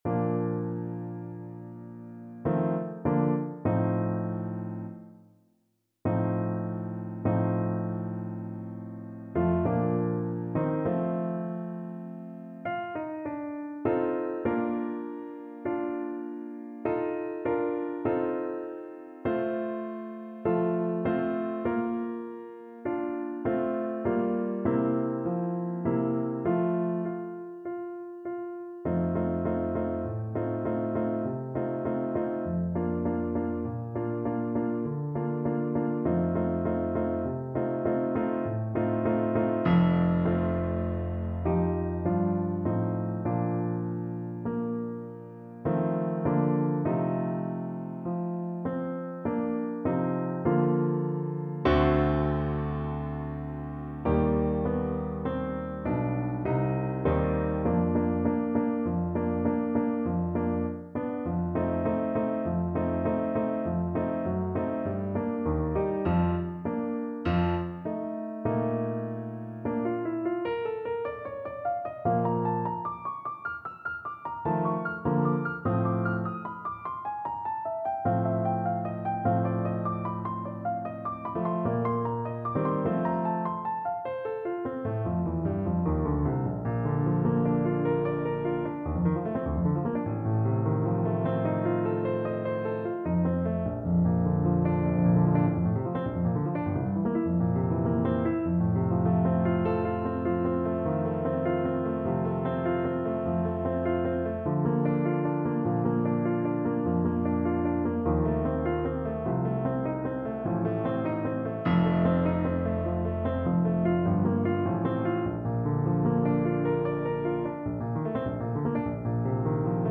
• Unlimited playalong tracks
Largo
Classical (View more Classical Clarinet Music)